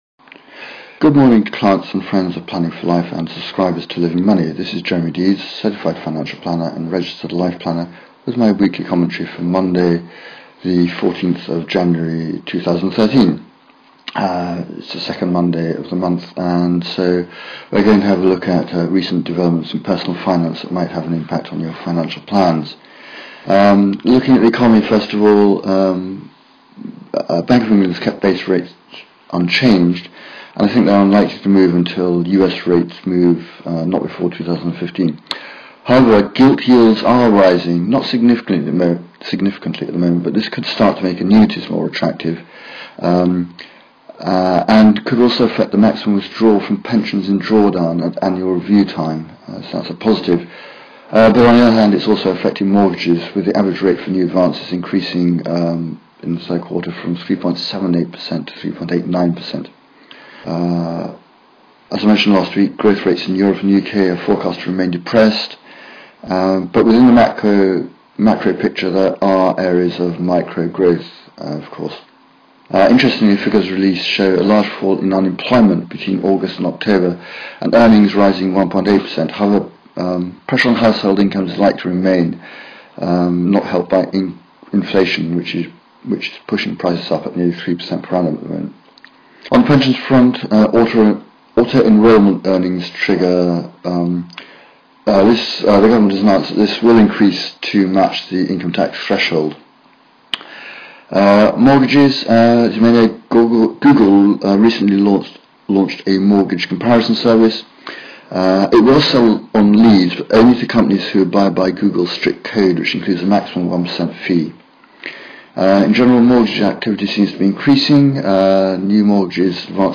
Weekly commentary, 14 January 2013